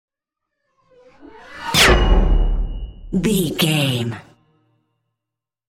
Sci fi whoosh to hit metal shot
Sound Effects
dark
futuristic
intense
woosh to hit